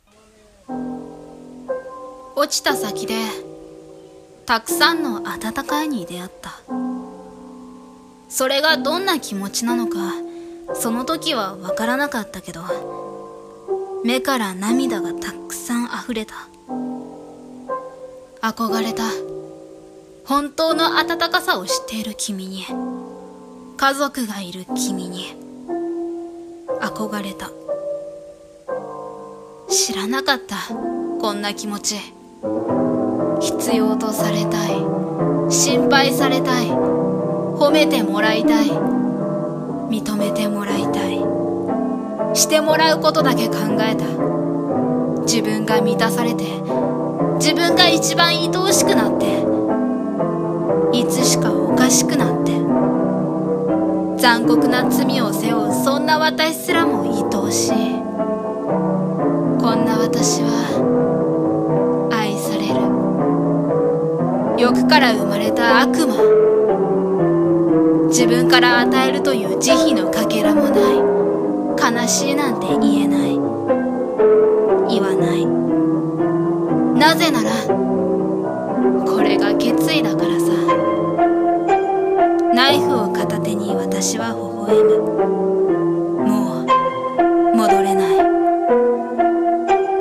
Undertale声劇 「I’m…」